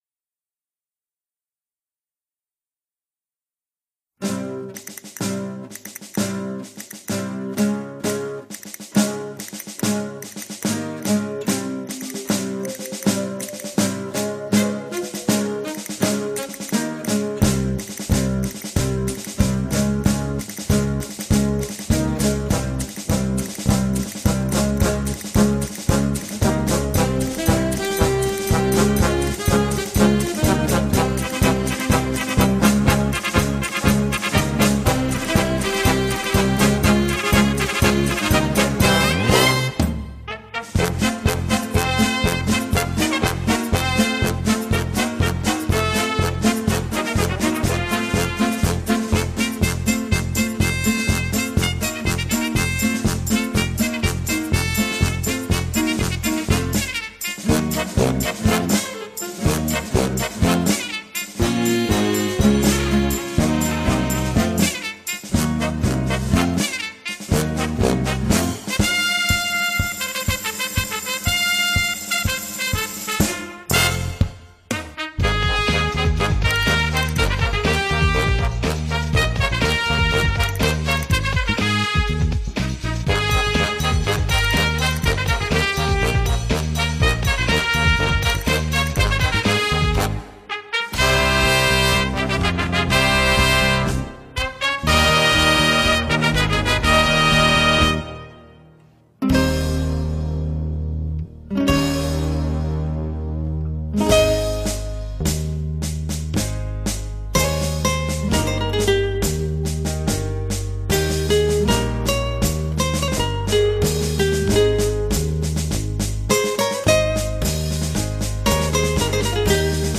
A monthly odyssey through a diverse pop landscape featuring new hits, throwbacks, remixes, and nu-disco.